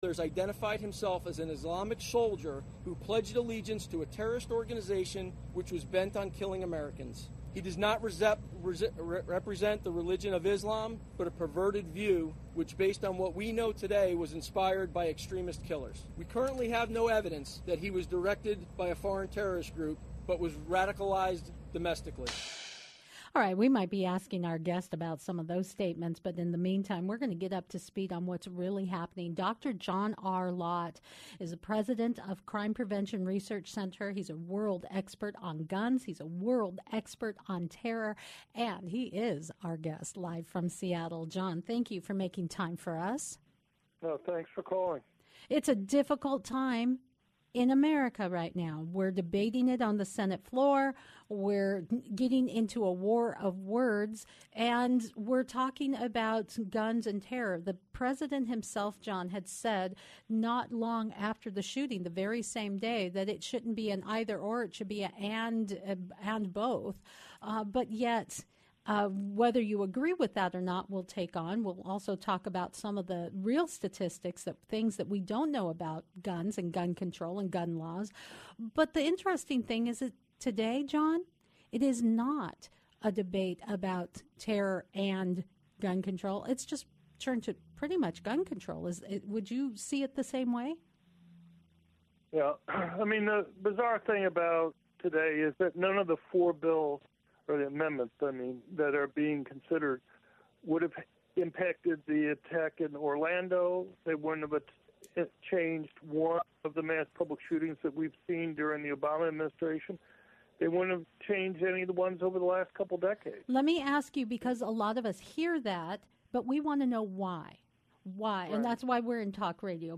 820AM KGNW (Live from Seattle)